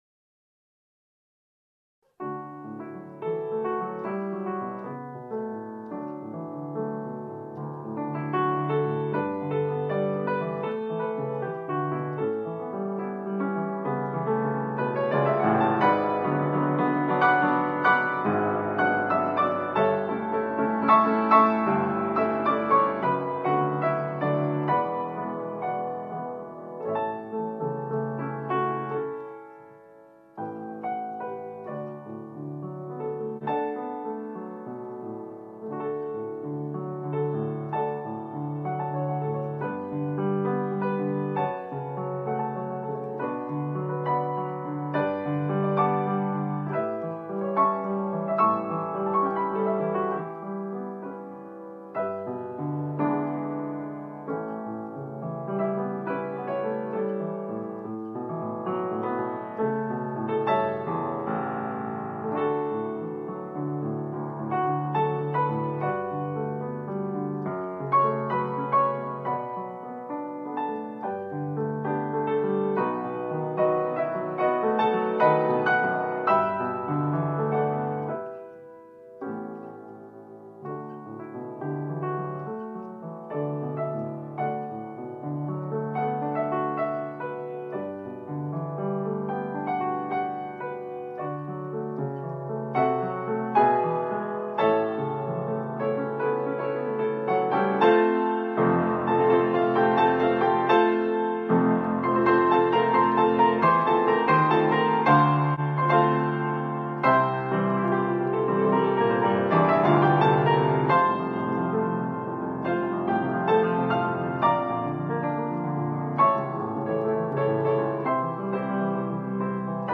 D调钢琴伴奏